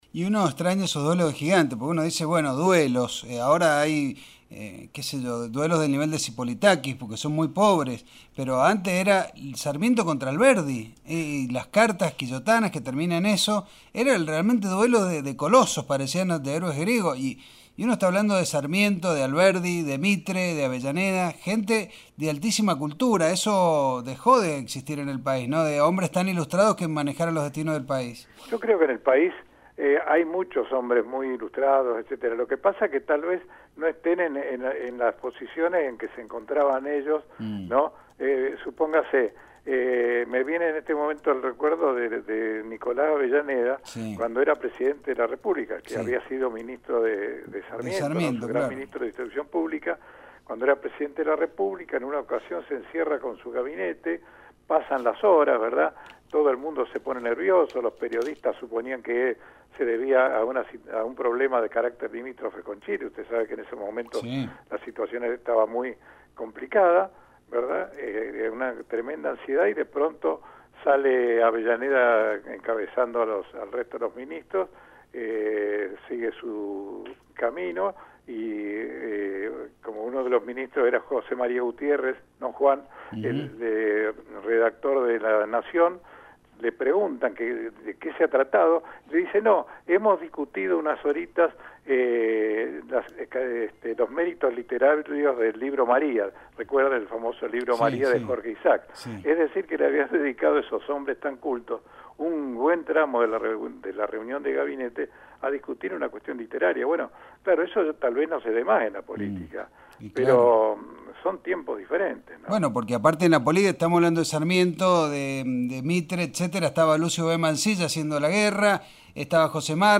Entrevista
por radio Nihuil, Mendoza. De su libro sobre Sarmiento.